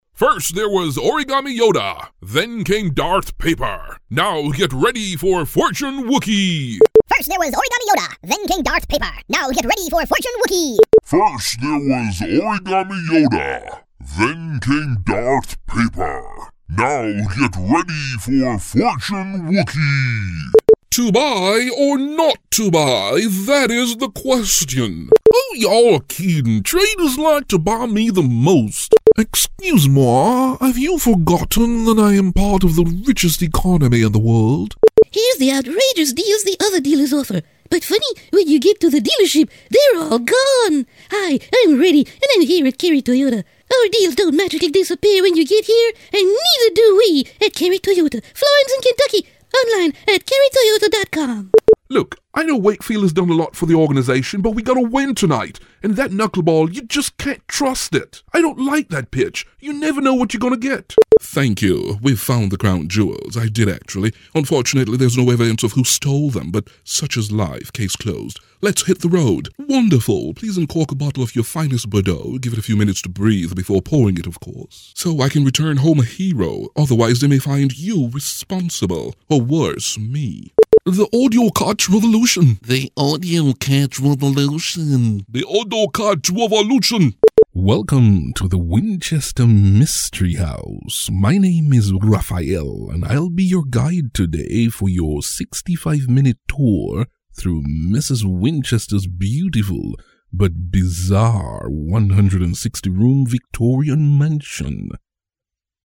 Jamaican native. Some have said: "...good command of the English language...", "...strong commanding voice with mellifluous baritones...", “...excitable, believable and reassuring...", “...very corporate for a professional setting...", "...very smooth and sexy...”
Sprechprobe: Sonstiges (Muttersprache):